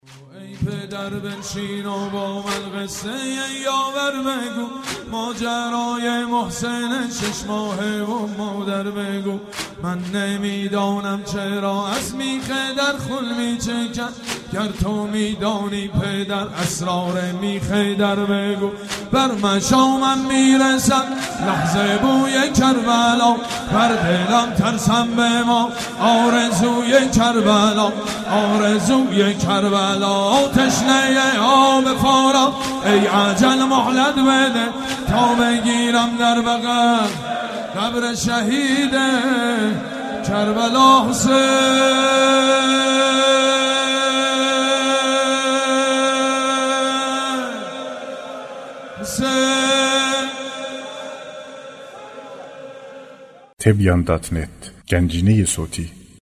مداحی و نوحه
سینه زنی، شهادت حضرت فاطمه زهرا(س